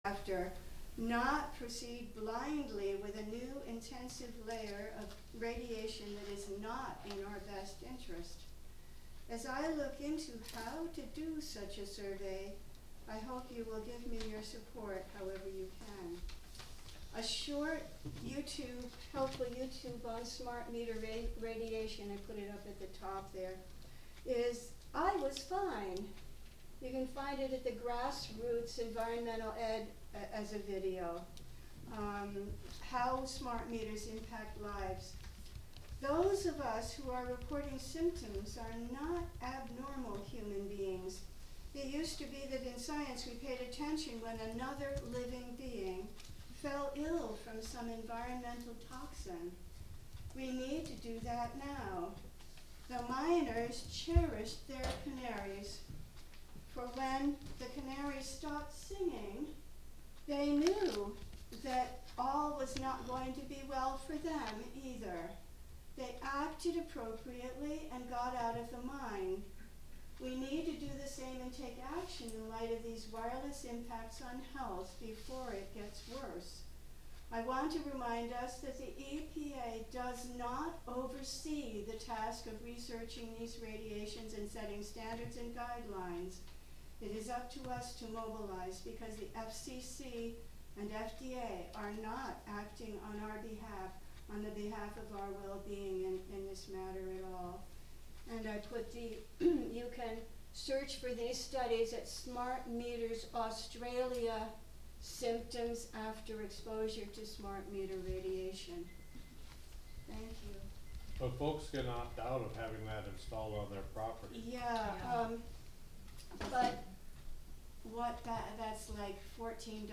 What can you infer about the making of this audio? Live from the Village of Philmont: Village Board Meeting (Audio)